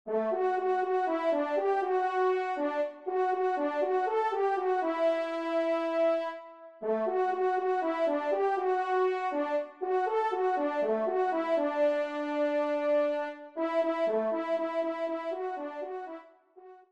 Trompe (Solo, Ton simple)